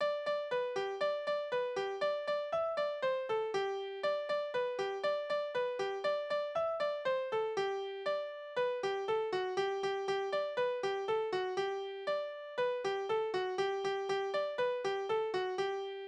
Kinderspiele: Katze und Maus
Tonart: G-Dur
Taktart: 2/4
Tonumfang: kleine Sexte
Anmerkung: - erste Vortragsbezeichnung: moderato - zweite Vortragsbezeichnung ist nicht lesbar - es gibt keinen Text